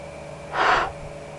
Blow Sound Effect
Download a high-quality blow sound effect.
blow-1.mp3